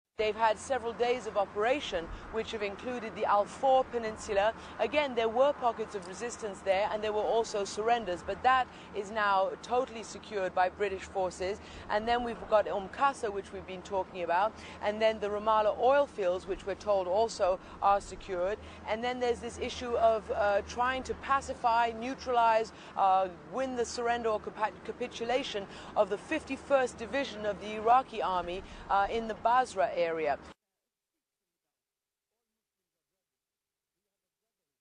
O postępach na południowo-wschodnim froncie mówi dla Radia Zet korespondentka CNN (271Kb)